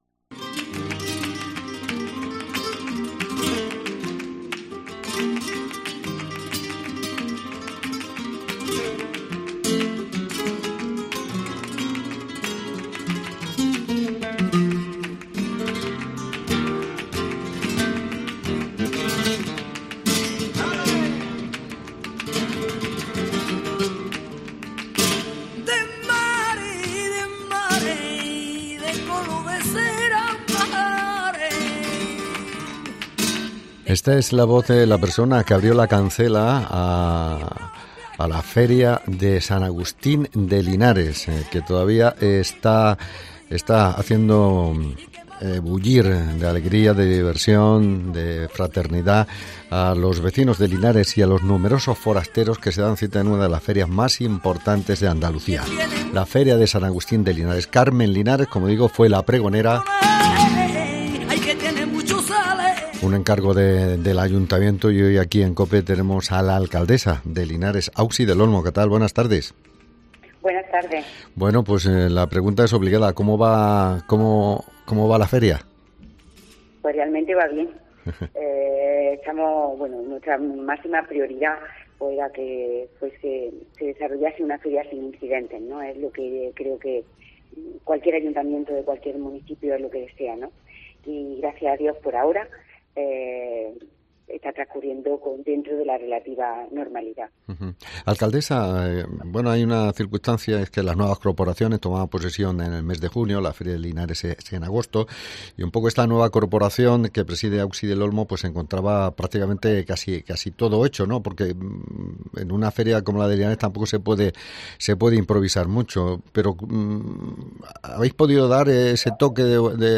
Entrevista con Auxi del Olmo, alcadesa de Linares sobre San Agustín 2023